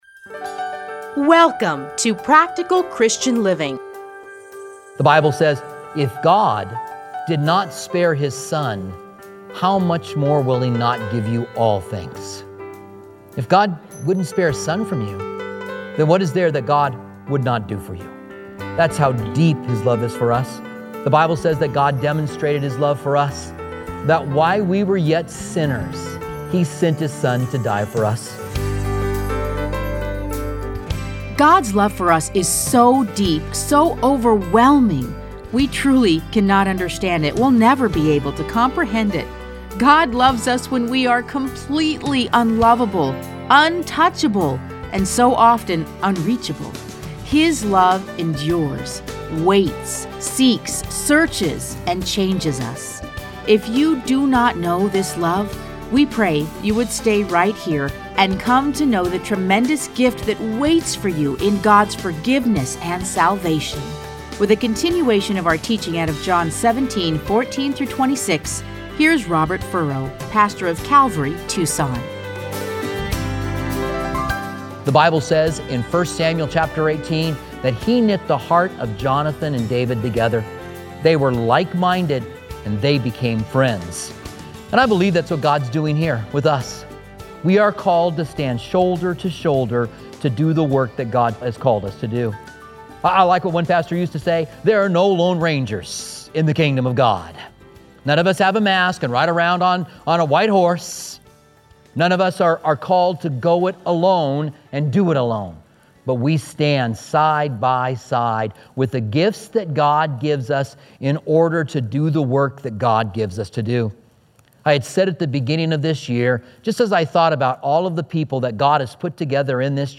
Listen to a teaching from John 17:14-26.